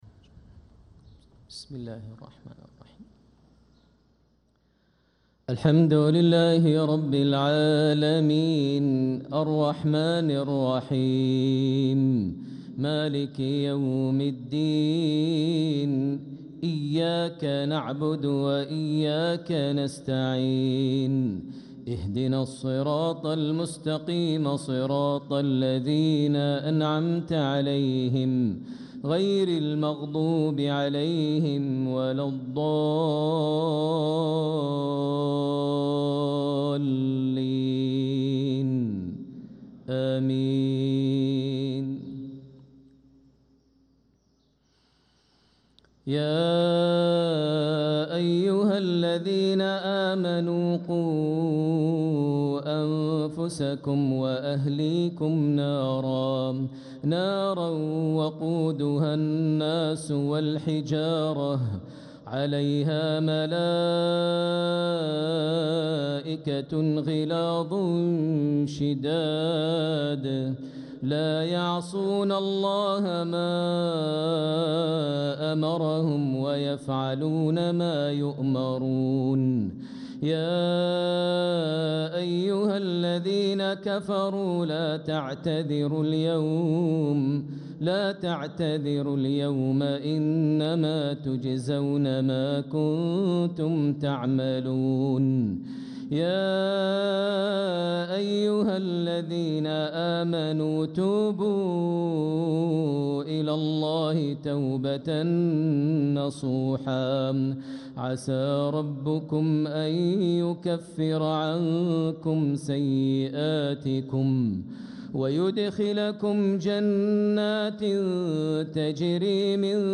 صلاة المغرب للقارئ ماهر المعيقلي 2 جمادي الأول 1446 هـ
تِلَاوَات الْحَرَمَيْن .